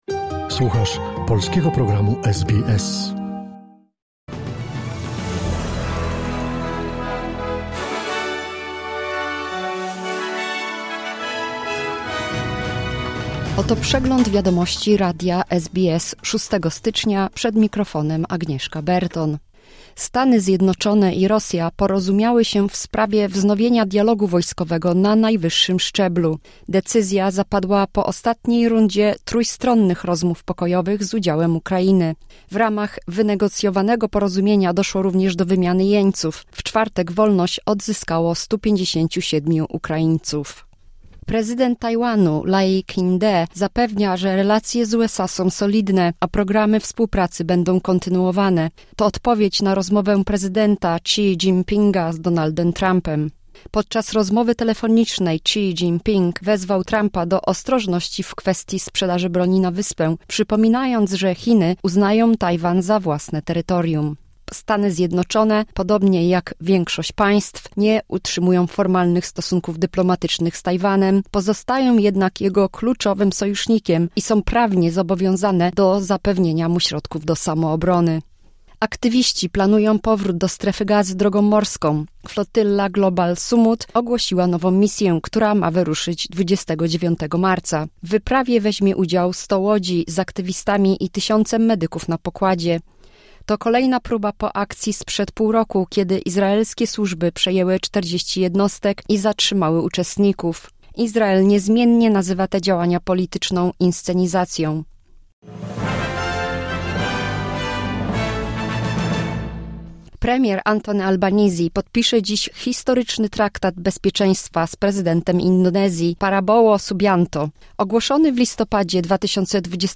Wiadomości 6 lutego SBS News Flash